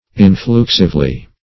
influxively - definition of influxively - synonyms, pronunciation, spelling from Free Dictionary Search Result for " influxively" : The Collaborative International Dictionary of English v.0.48: Influxively \In*flux"ive*ly\, adv.
influxively.mp3